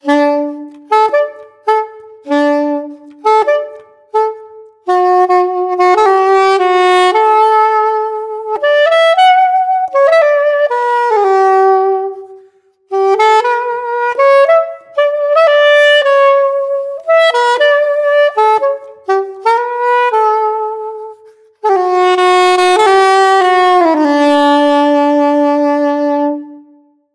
ساکسوفون سوپرانو YAMAHA YSS-475
صدای محصول
Classical
5255-Classical.mp3